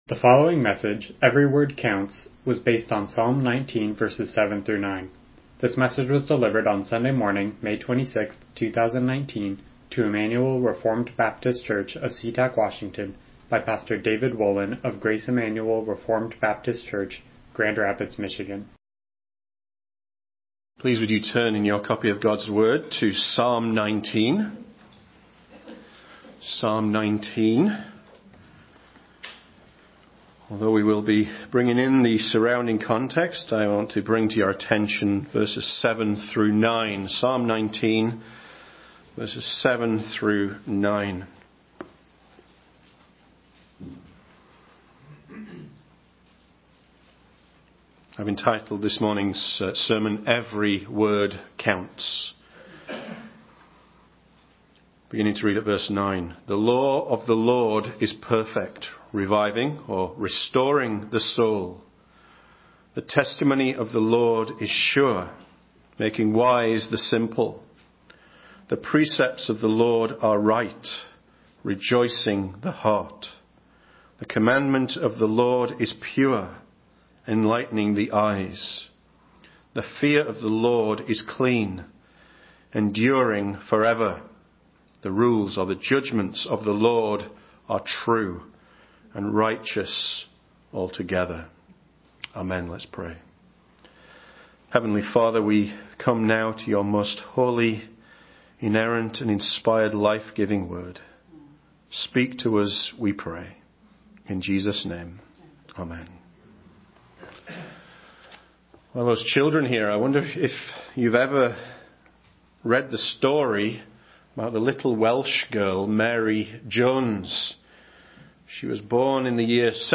Psalm 19:7-9 Service Type: Morning Worship « Was the New Testament Canon Chosen or Recognized?